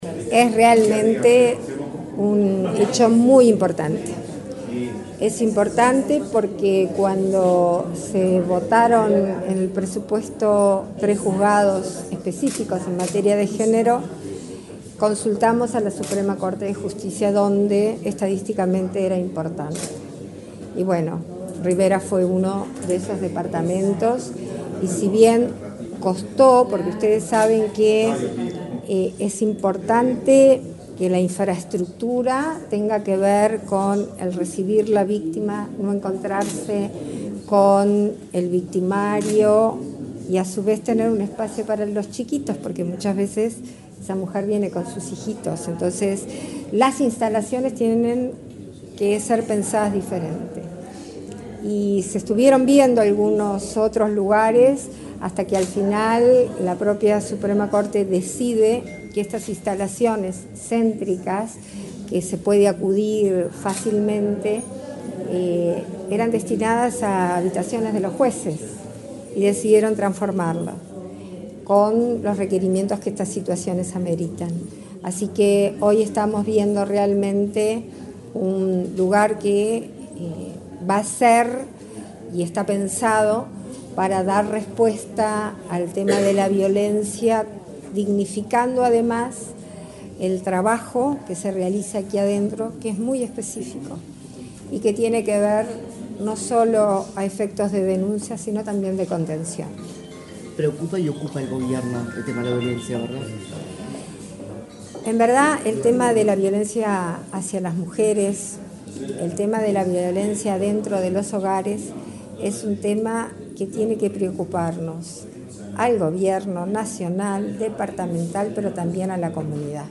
Declaraciones de la presidenta en ejercicio, Beatriz Argimón
Este viernes 16, la presidenta de la República en ejercicio, Beatriz Argimón, dialogó con la prensa, durante la inauguración de dos juzgados